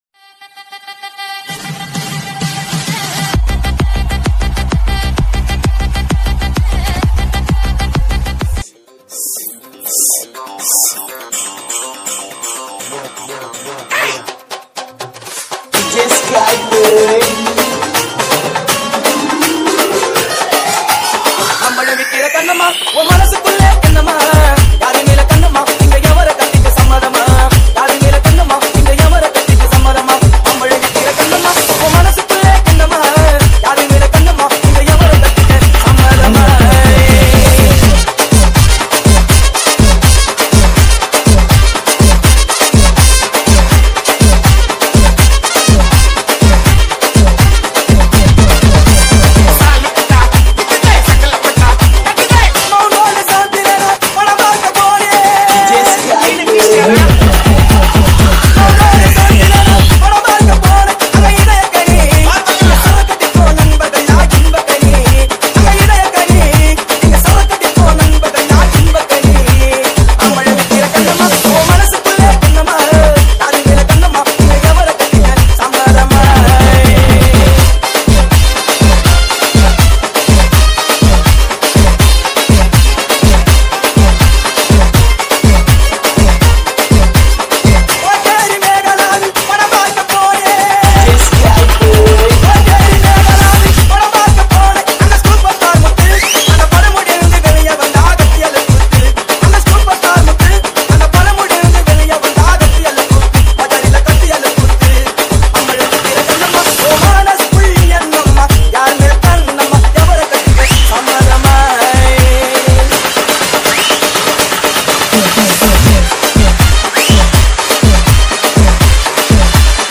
TAMIL ITEM DJ REMIX SONG